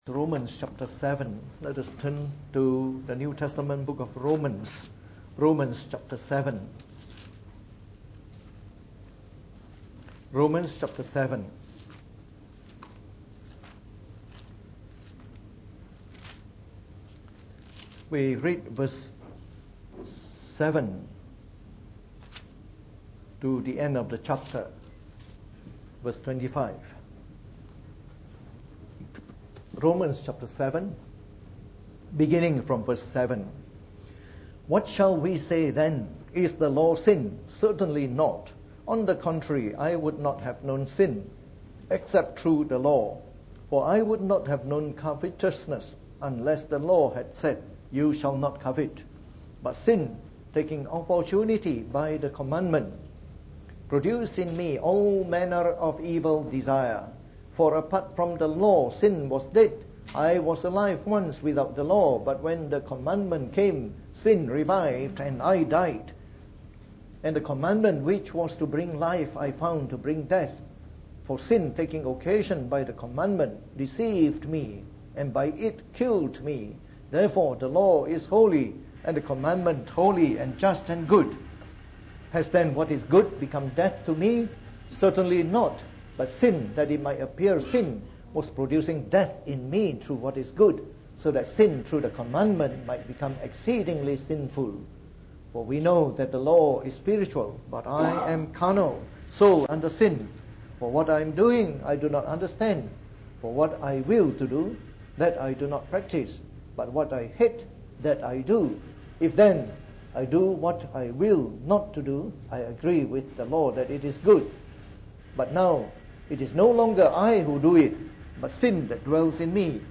From our series on the Book of Romans delivered in the Morning Service.